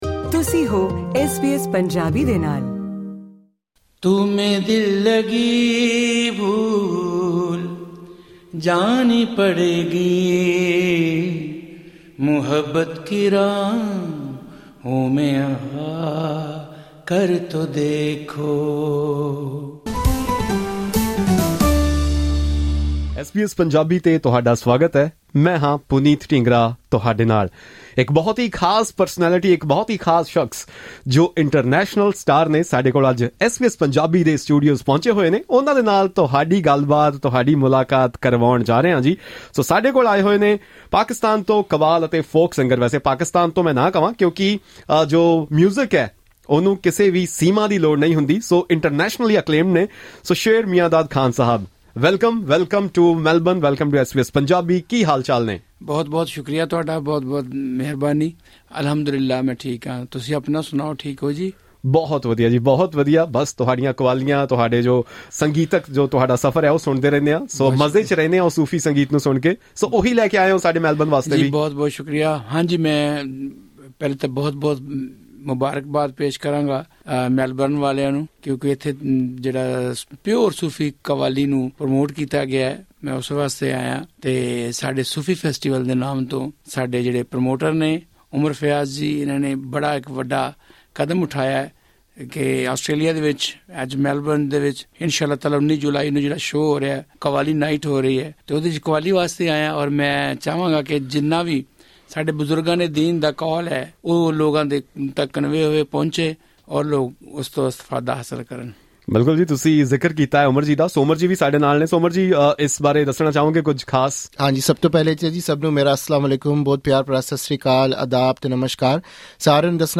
Renowned Qawwal and folk singer from Pakistan, Sher Mian Dad Khan Sahib, visited the SBS Punjabi studio in Melbourne, where he performed several popular Qawwalis and shared insights about the structure of Qawwali. During the visit, he mentioned that in 2005, he had traveled to Jalandhar to visit the place of his ancestors, where his musical lineage began.
Listen to this interview for Khan Sahib’s fascinating stories.